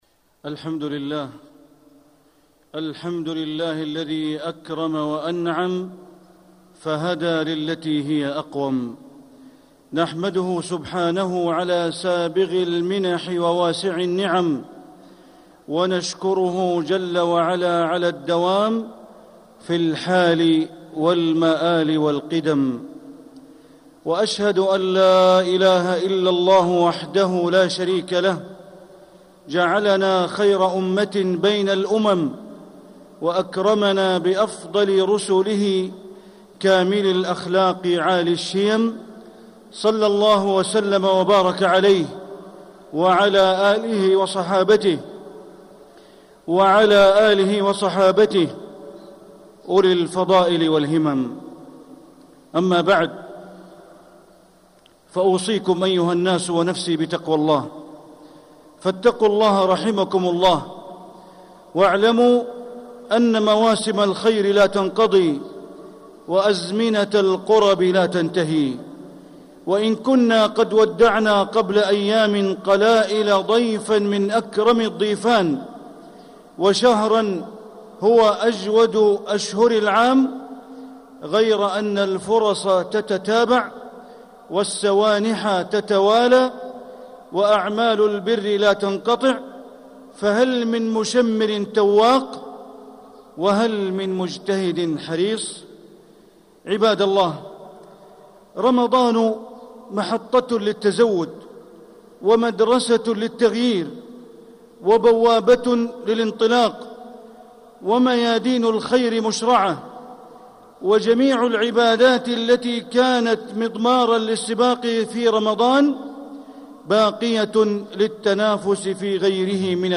مكة: مداومة العمل بعد رمضان - بندر بن عبد العزيز بليلة (صوت - جودة عالية